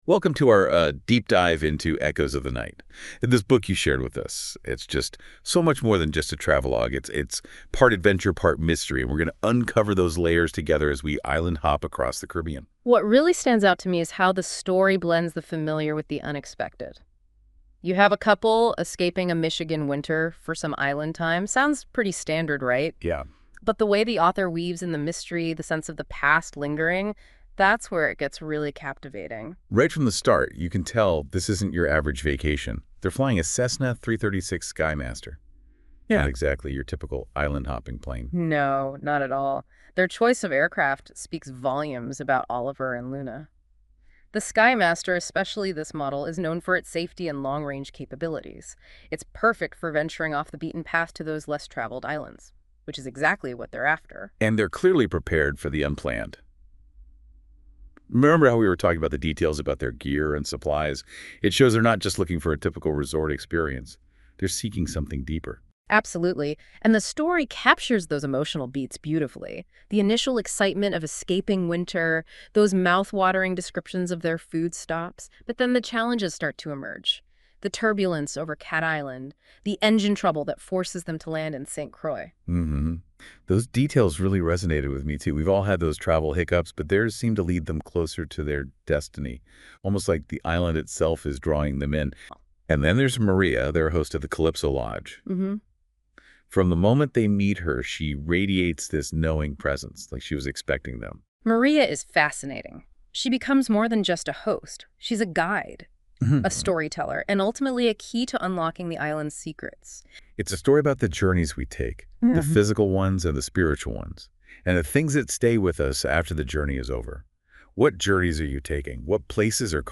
AIPodcastTeaserforEchoesoftheNightNoIntroExit.mp3